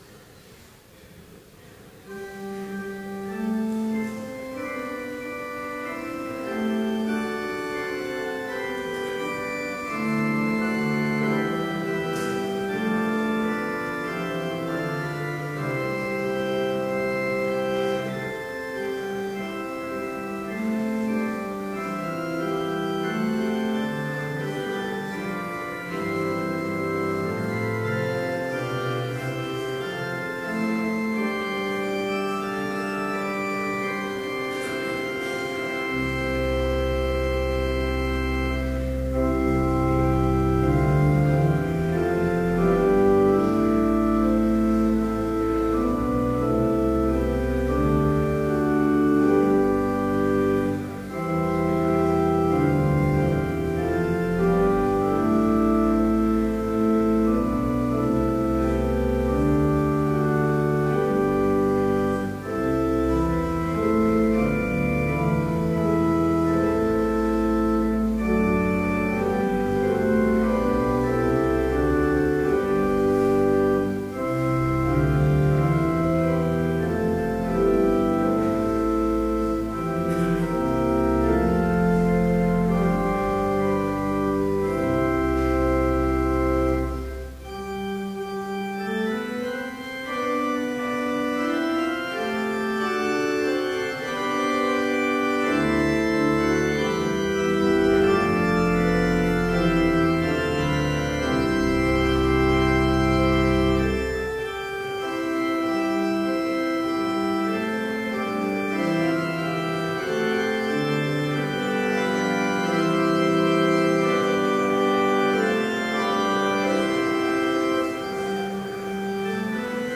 Complete service audio for Summer Chapel - August 15, 2012